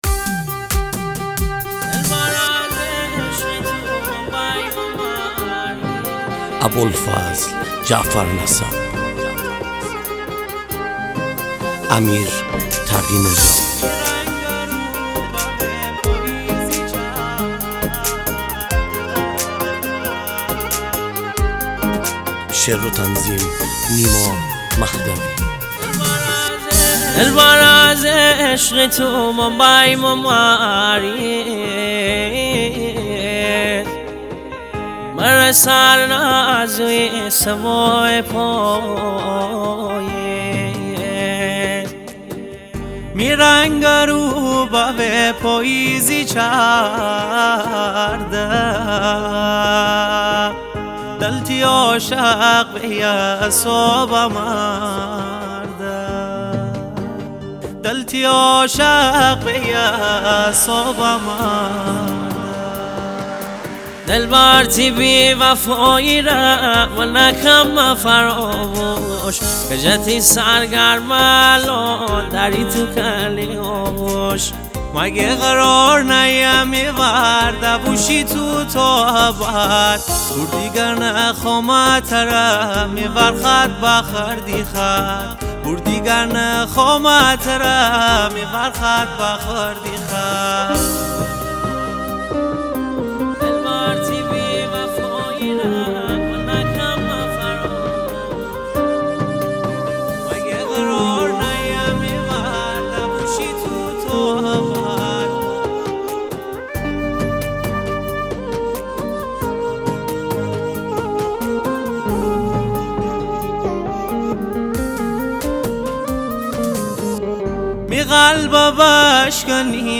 ریتمیک